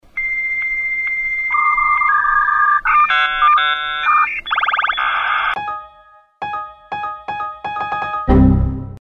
Знакомый шум установления соединения перенесет вас назад в 90-е. Все файлы доступны в высоком качестве для личного использования или творческих проектов.
Звук модема с эффектом зависания